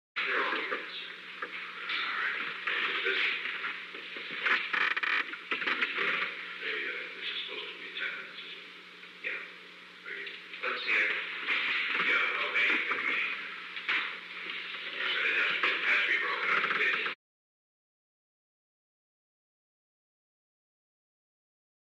Conversation: 867-012
Recording Device: Oval Office
The Oval Office taping system captured this recording, which is known as Conversation 867-012 of the White House Tapes.
The President met with an unknown man.